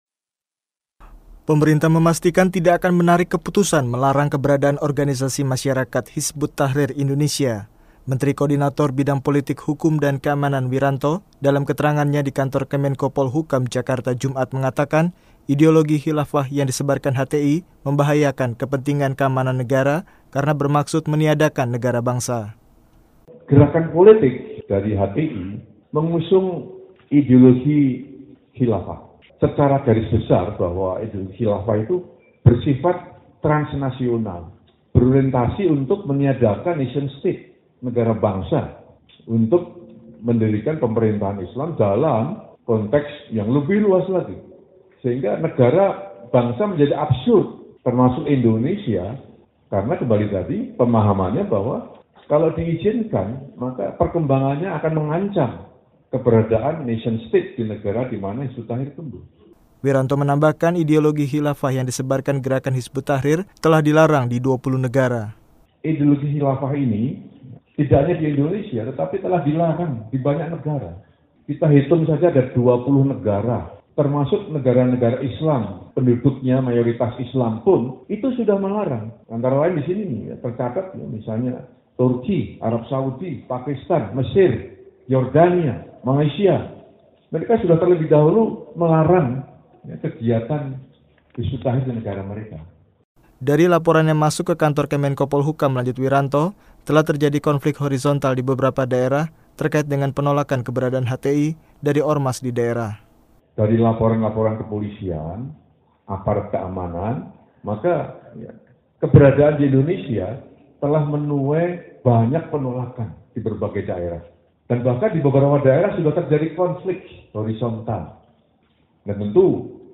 melaporkannya dari Jakarta.